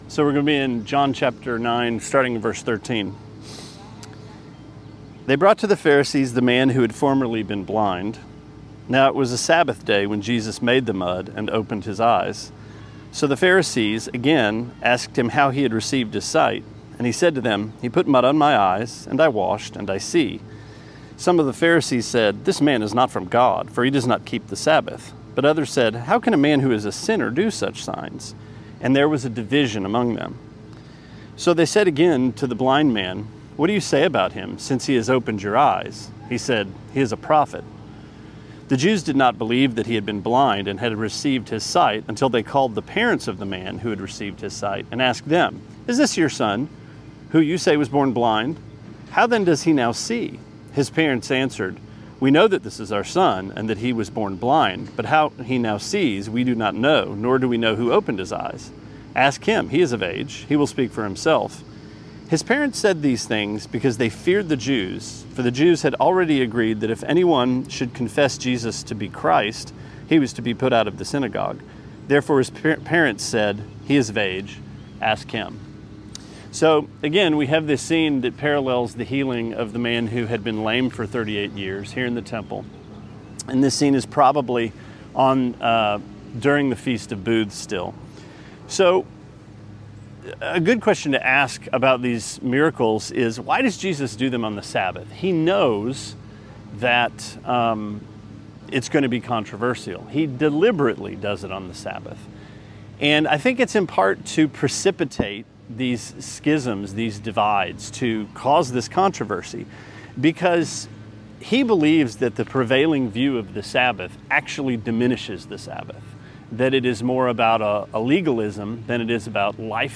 Sermonette 5/17: John 9:13-27: Schism